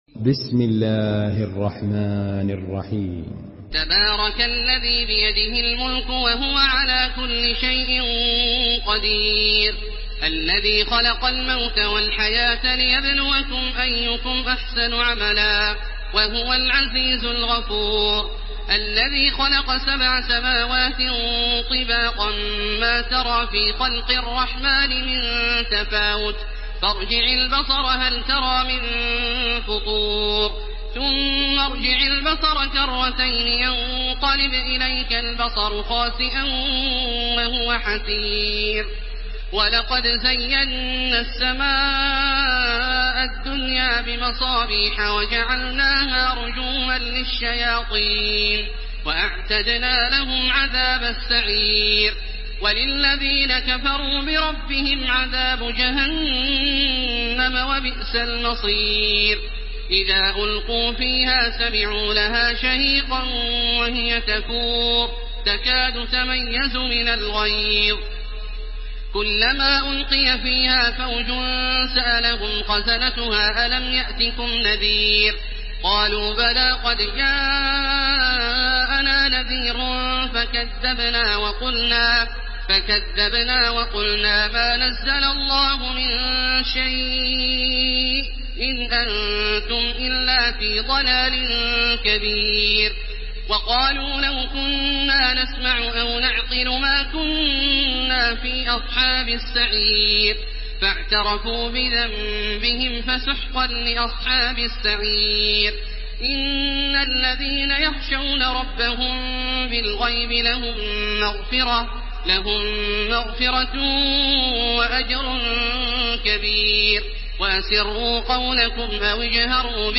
Surah الملك MP3 by تراويح الحرم المكي 1430 in حفص عن عاصم narration.
مرتل